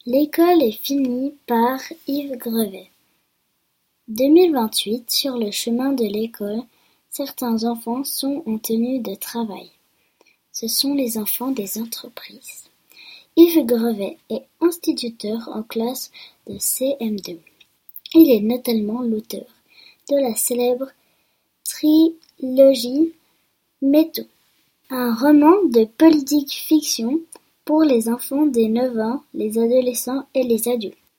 Clique sur « play » pour écouter le texte qui se trouve sur la quatrième de couverture.